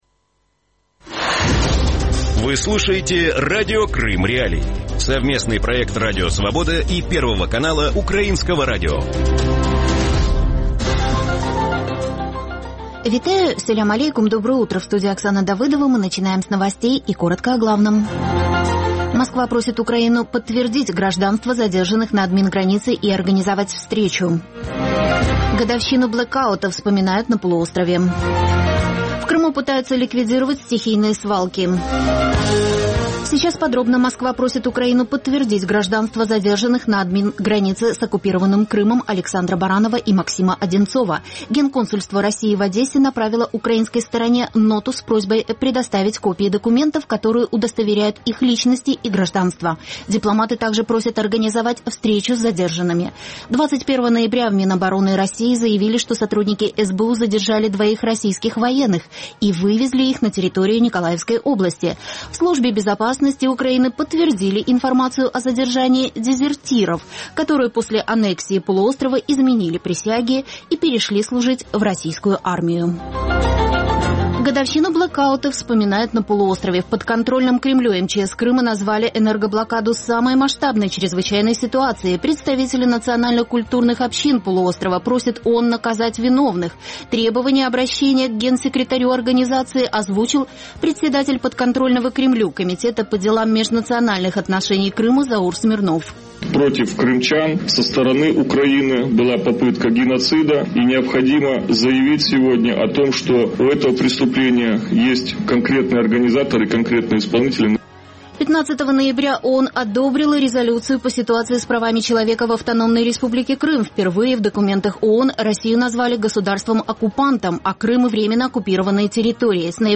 Ранковий ефір новин про події в Криму. Усе найважливіше, що сталося станом на цю годину.